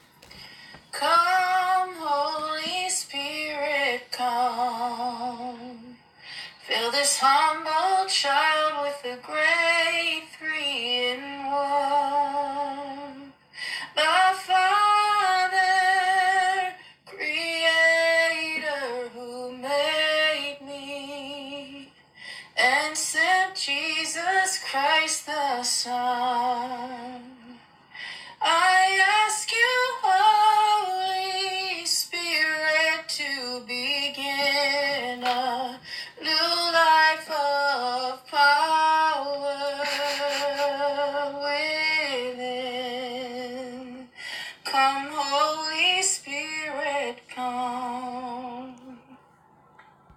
a lovely voice - tenor?